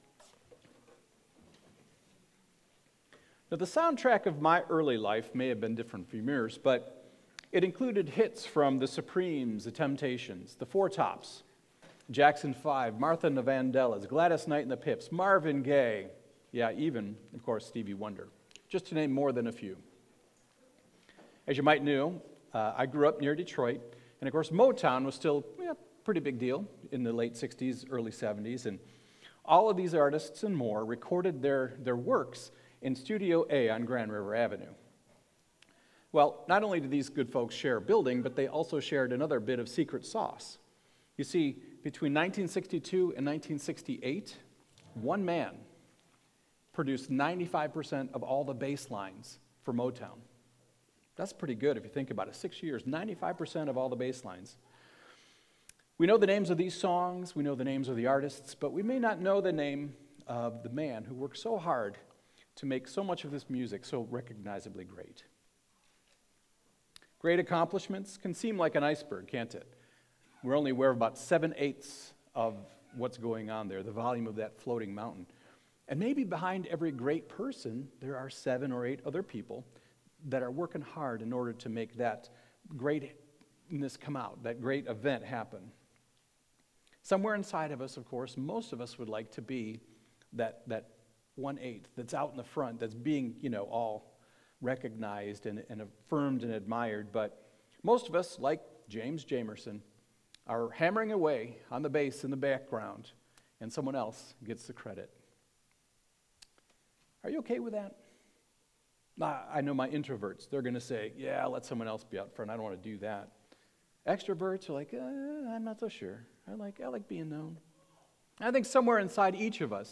A message from the series "Special Guest Speakers."